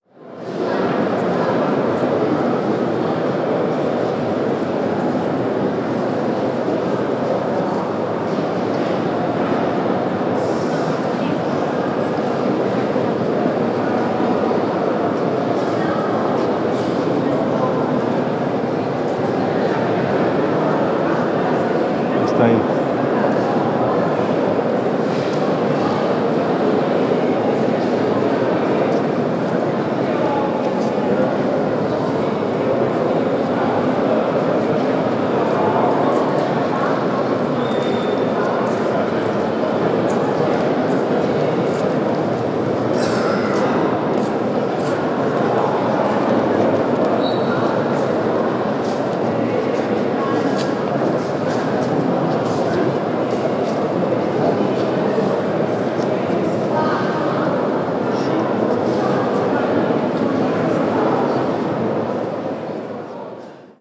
Ambiente tranquilo de las calles de Santiago de Compostela, A Coruña
voz
tránsito
tos
bullicio
Sonidos: Gente
Sonidos: Ciudad